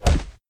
sounds_melee_hit_02.ogg